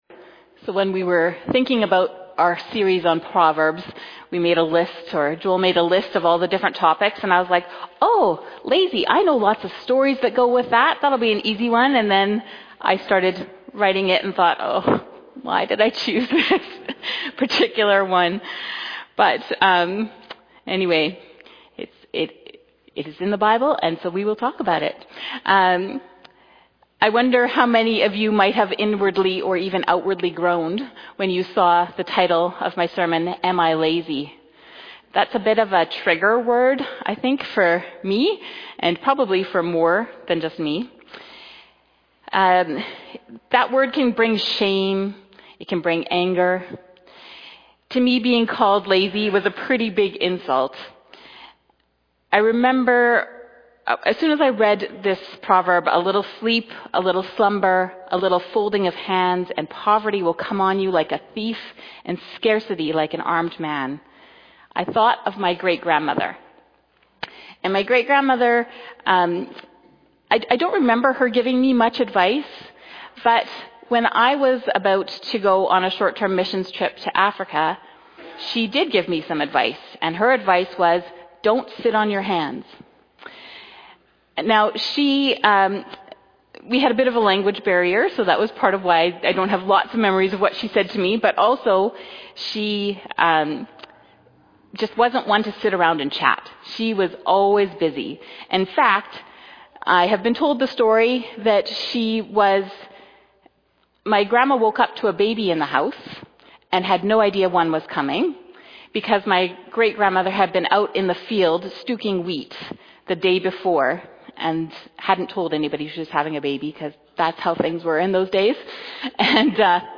2025 Sermon July 20 2025